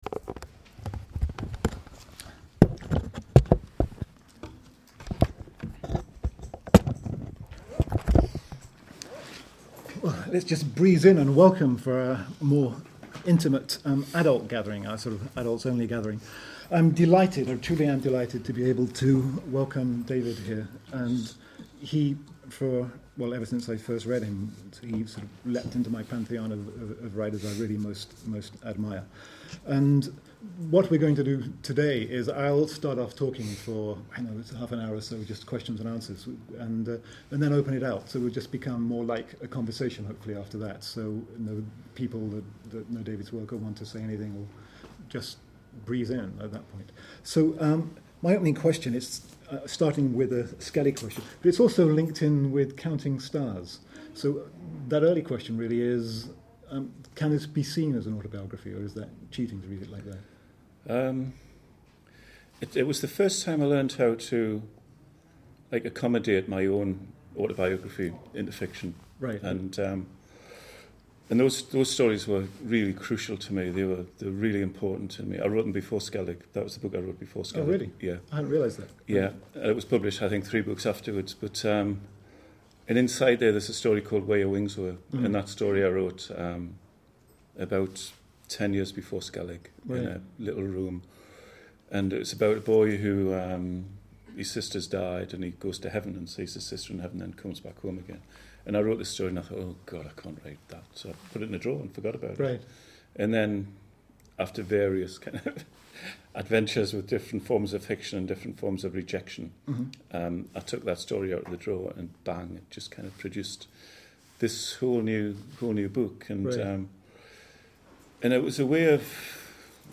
An interview with David Almond following his participation in the University of Hull's 2nd Annual Children's Writing Event.